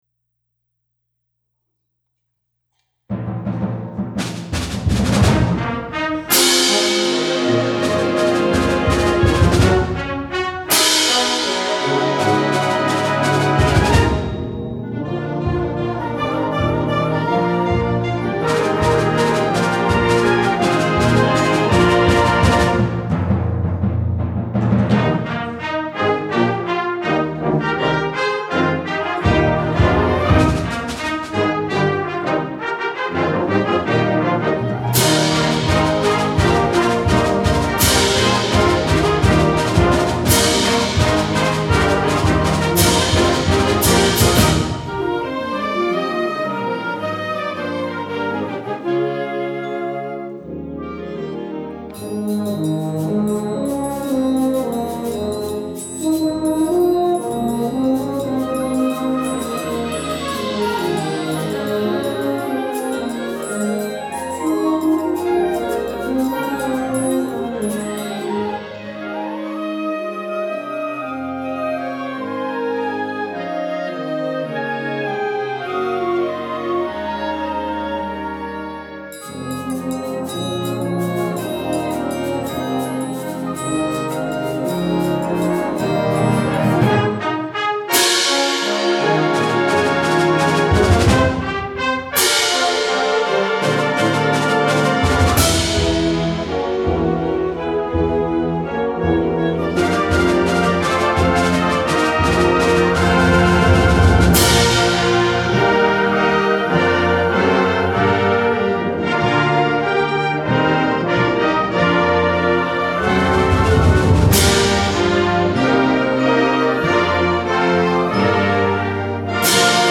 Winter Concert
--SYMPHONIC BAND--